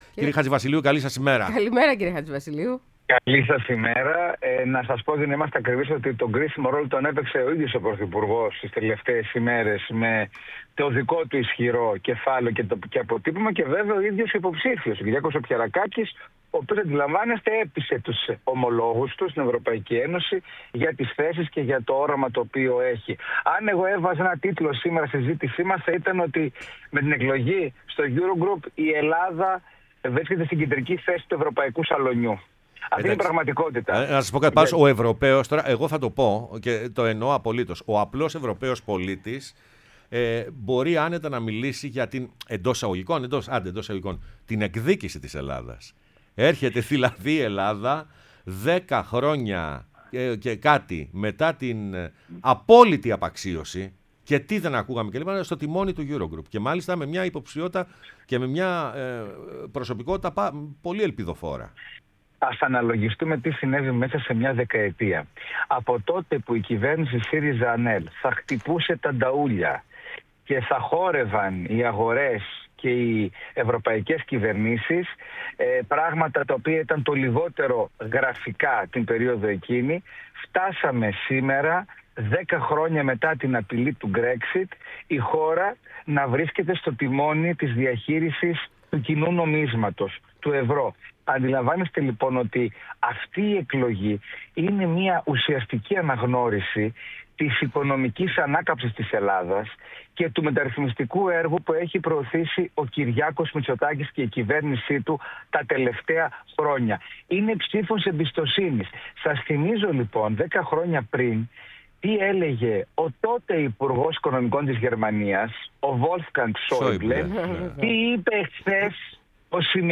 O Τάσος Χατζηβασιλείου , διεθνολόγος και βουλευτής ΝΔ μίλησε στην εκπομπή Πρωινές Διαδρομές